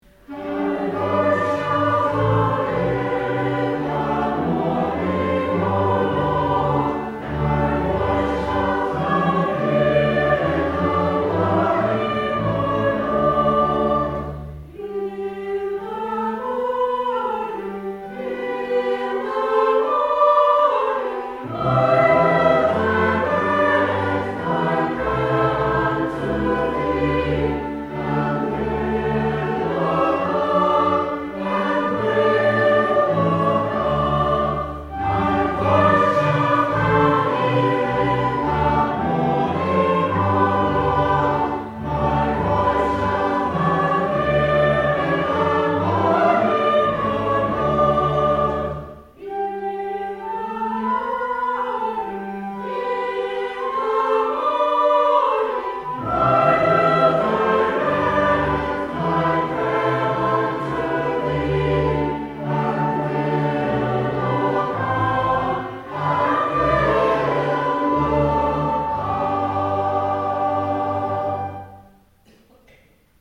This was recorded on 4 October 2025, during a West Gallery music day with this ad hoc choir and band of instruments.